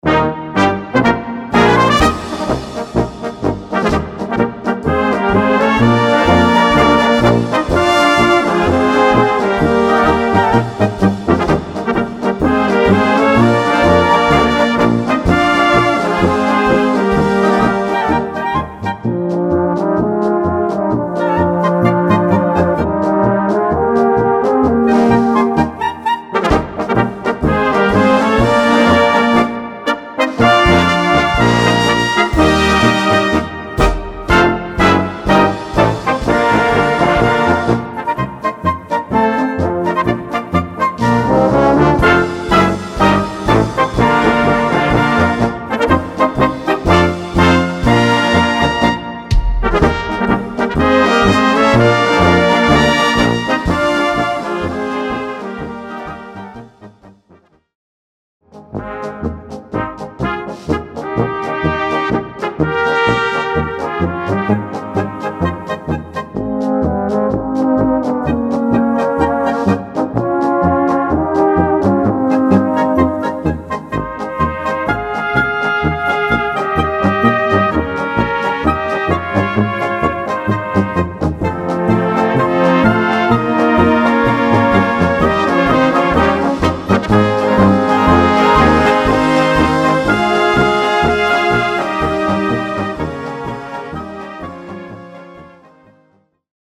Gattung: Polka
Besetzung: Blasorchester
schöne, schmissige und universell einsetzbare Polka